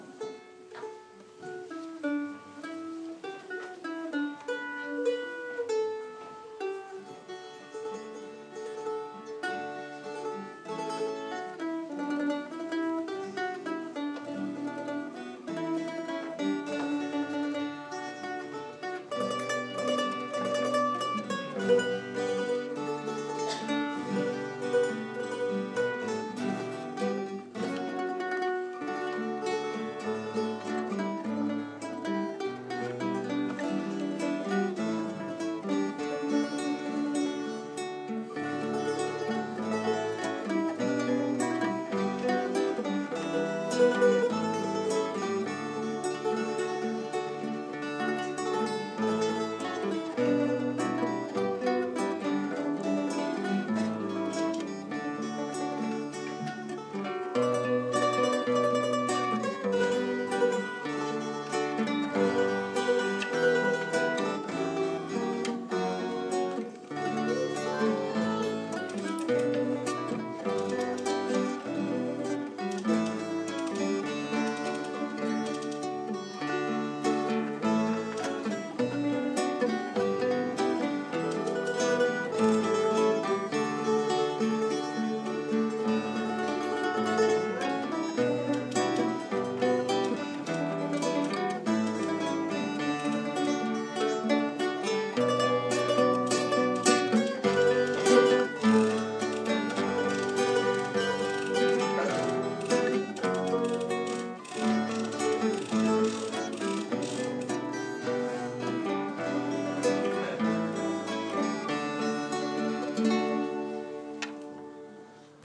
Church Music